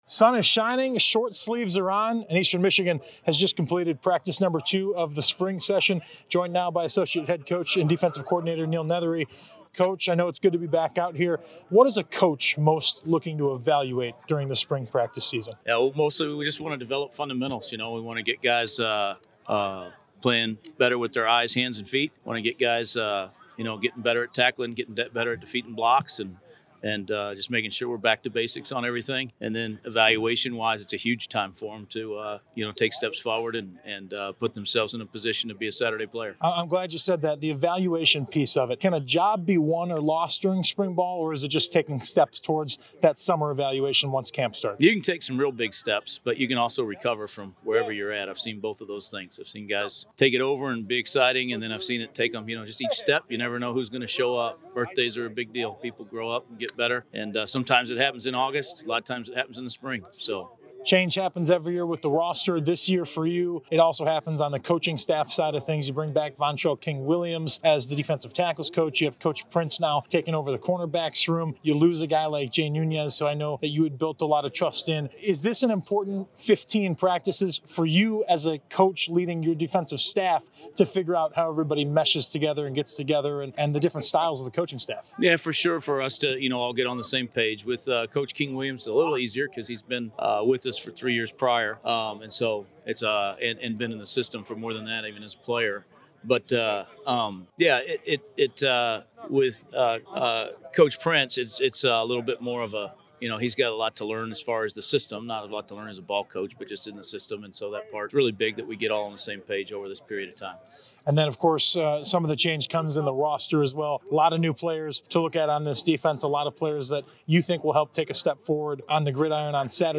From the Field - Day Two: Football Spring Practice Interview Series